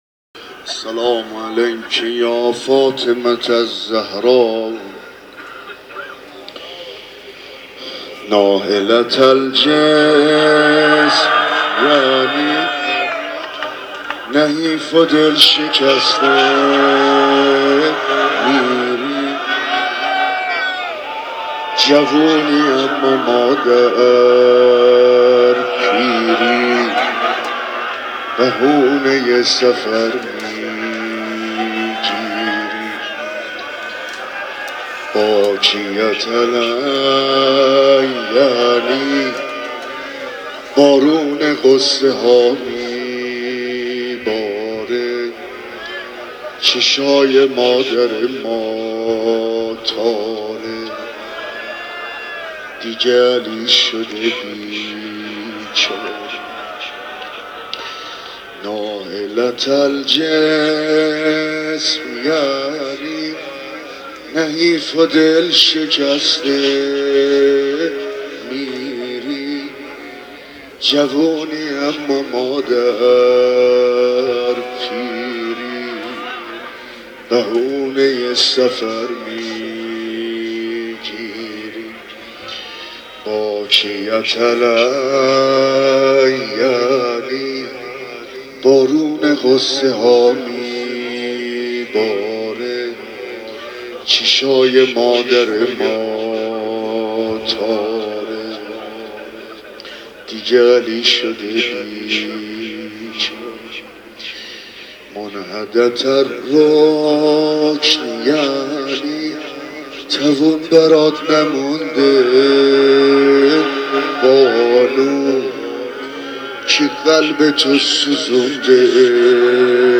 روضه بدرقه فاطمیه حاج عبدالرضا هلالی.m4a